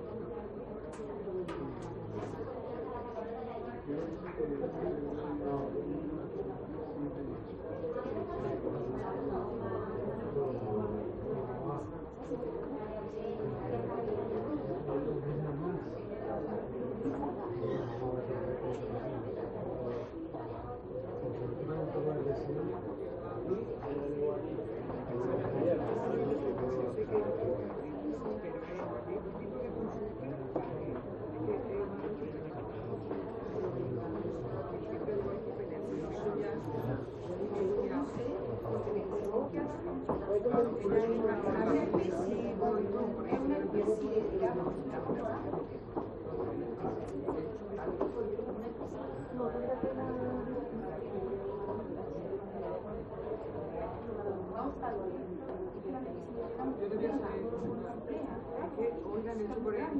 Hospital.ogg